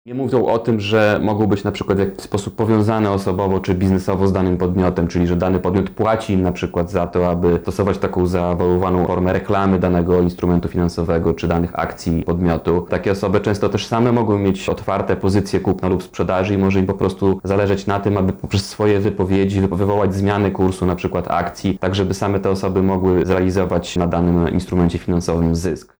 Nie w banku, nie w instytucji parabankowej, ale w instytucji , którą nadzoruje Samorząd Województwa Lubelskiego – mówi Jarosław Stawiarski, Marszałek Województwa Lubelskiego: